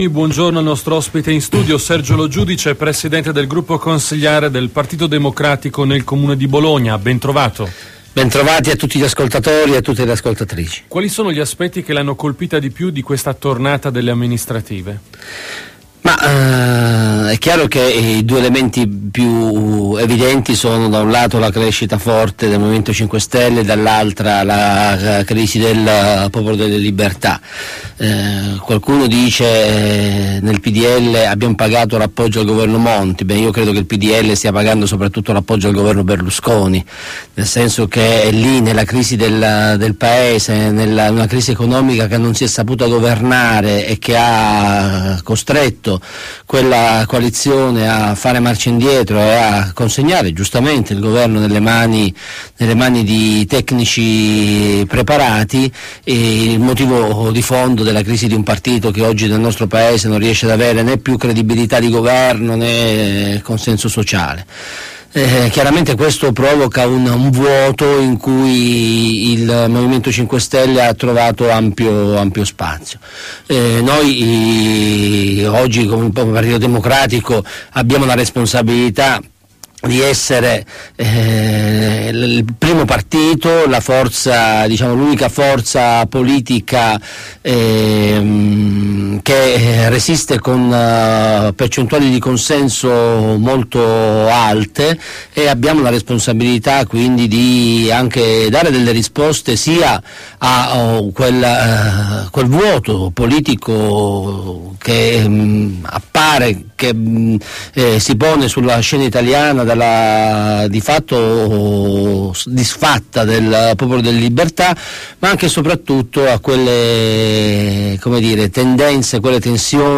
Il Presidente del gruppo consiliare PD Sergio Lo Giudice fornisce gli ultimi aggiornamenti sui principali temi d'attualità politica nell'intervista effettuata durante la trasmissione Detto tra noi